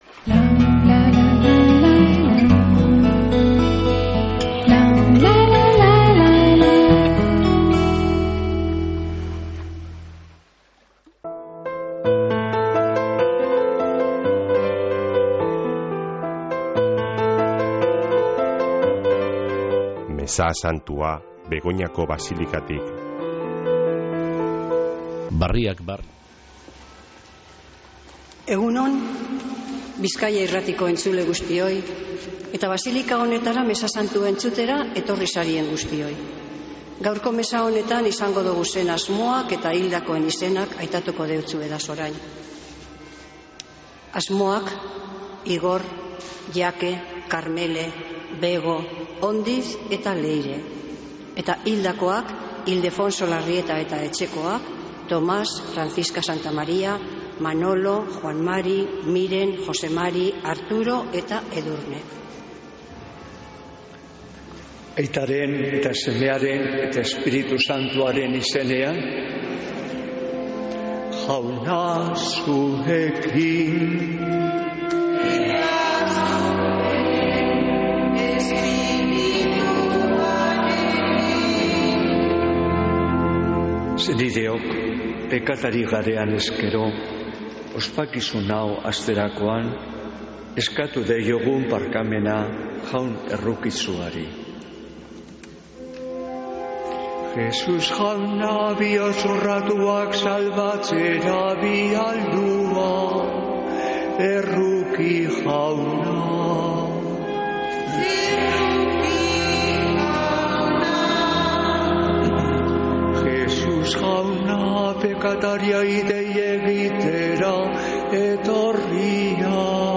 Mezea (26-01-13) | Bizkaia Irratia